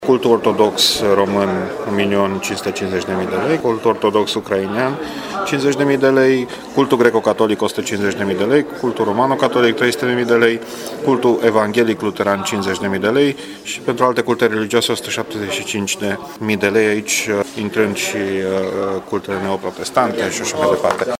Viceprimarul Dan Diaconu spune că banii au fost împărţiţi în funcţie de numărul de enoriaşi.